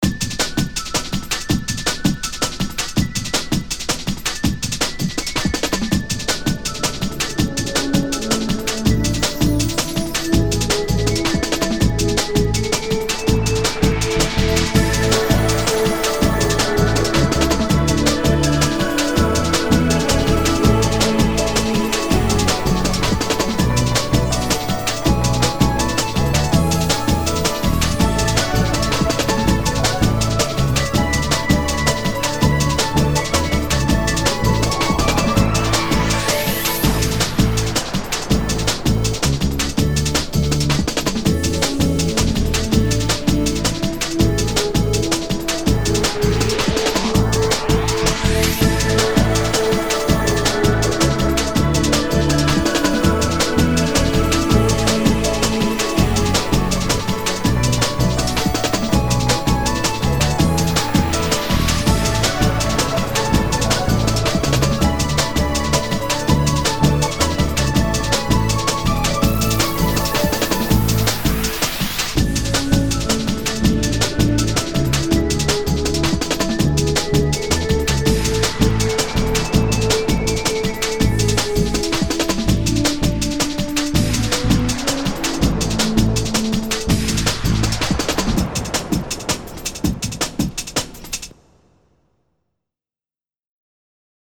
ボコーダートラック入りと、無し・・・。